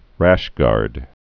(răshgärd)